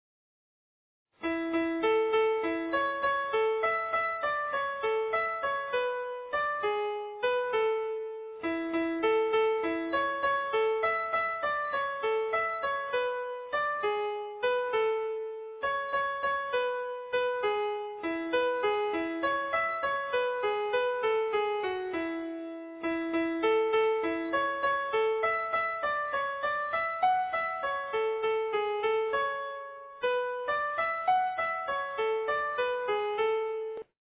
séquencé